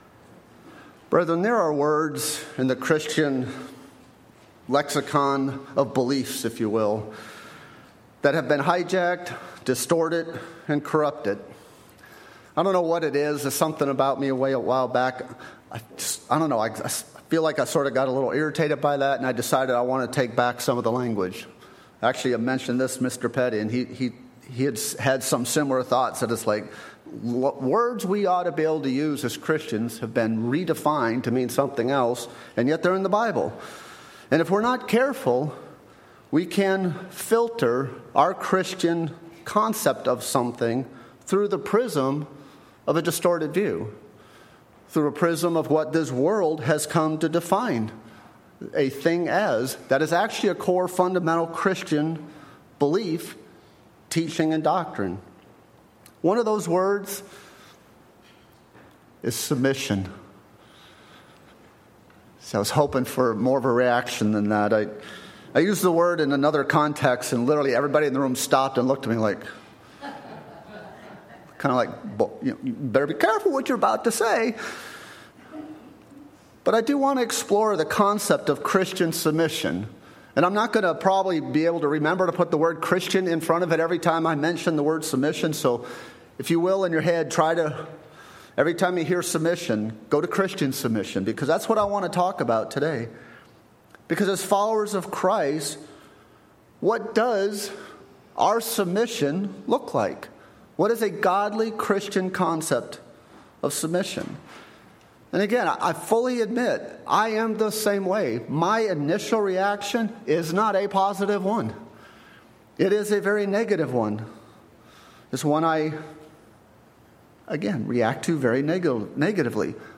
Words can be redefined in common usage that change their original intent, including words that are part of our Christian faith. In this sermon, we explore the concept of Christian submission from the scriptures.
Given in Nashville, TN